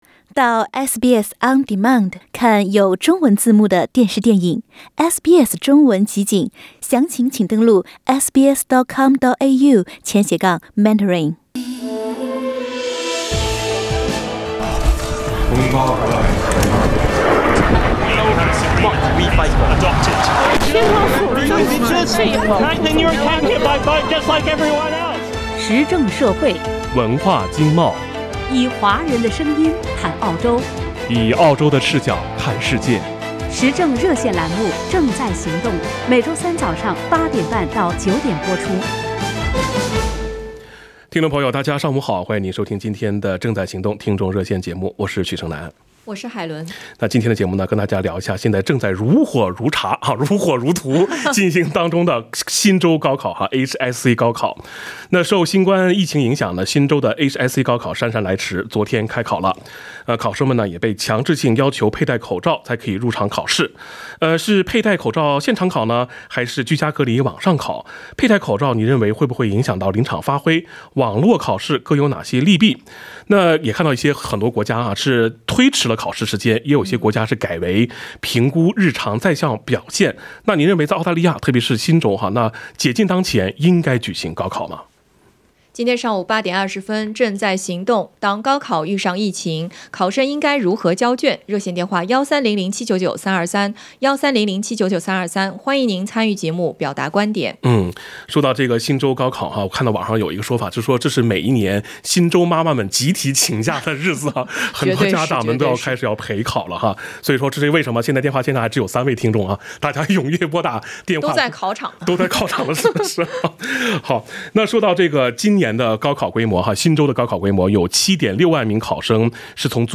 （点击封面图片，收听热线回放）
热线听众仅代表其个人观点，不代表本台立场，仅供参考。